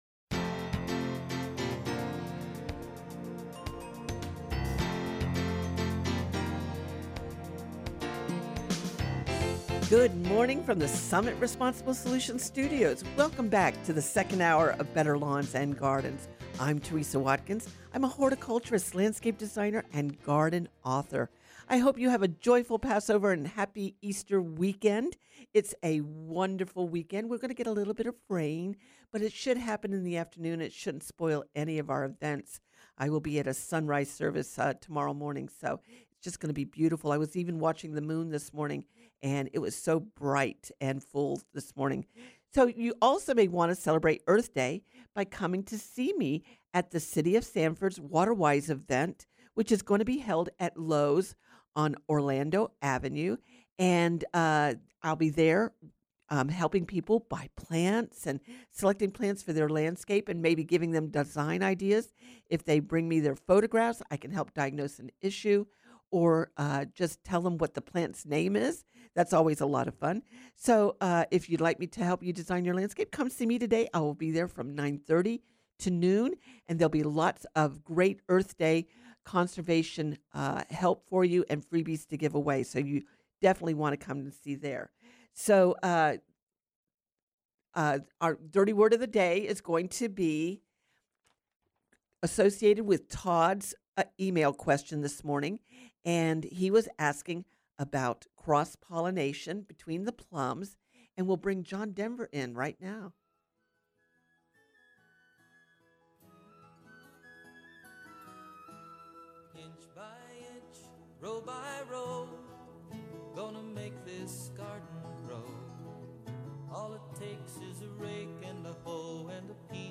With nothing but my wits and Series 4 De-Atomizer, I answer your gardening questions on Better Lawns and Gardens.
Garden questions and texts include lemon tree, soil for vegetable beds, fertilizer for azaleas, crapemyrtles, lawns, and fruit trees, how to pollinate zucchini, avocado, freeze-damaged firebush,, growing amaryllis, little sprouting oak trees, and more. https